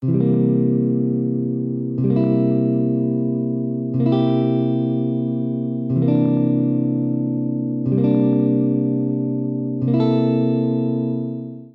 Fretmaps, Dominant Chords
Below are some selected examples of dominant chord voicings (listen to the chords in mp3 format).
dominant_chords.mp3